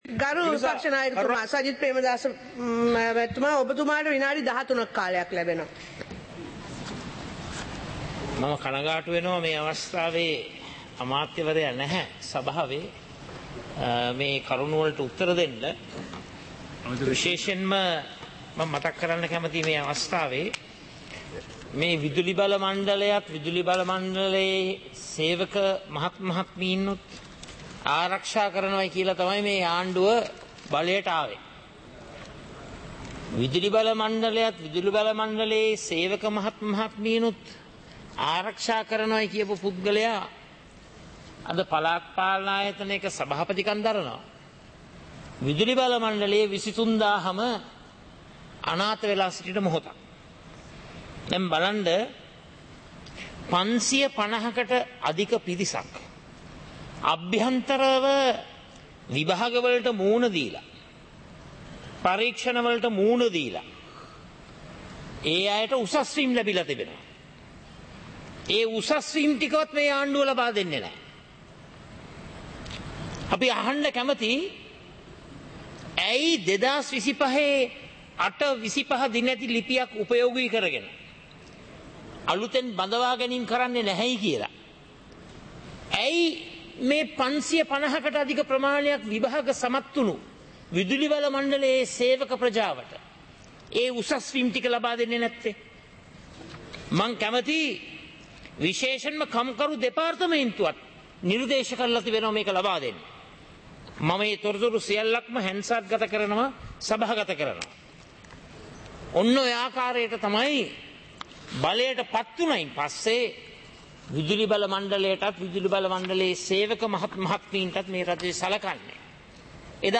පාර්ලිමේන්තුව සජීවීව - පටිගත කළ